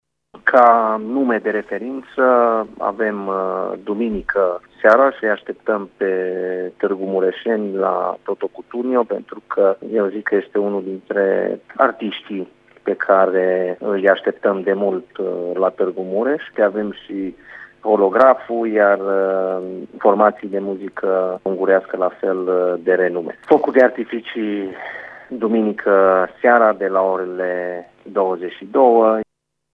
Revine viceprimarul Claudiu Maior: